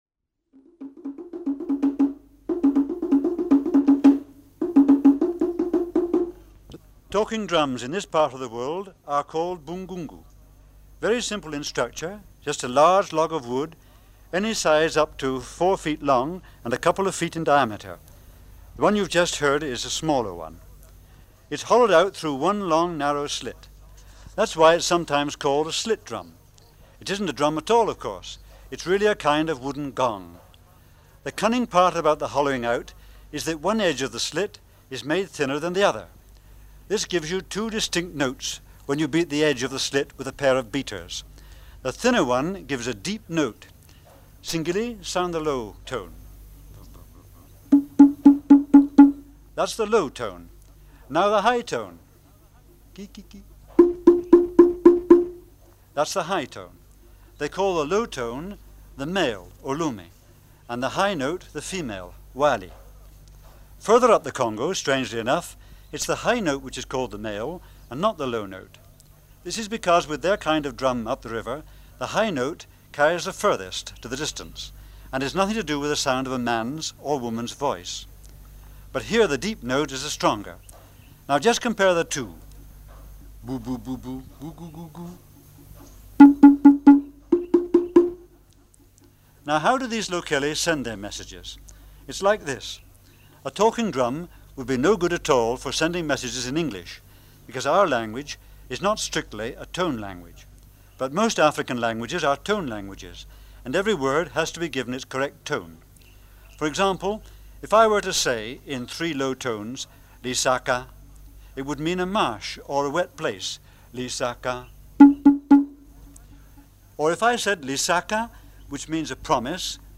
Talking drums of the Upper Congo TR129-01.mp3 of Talking drums of the Upper Congo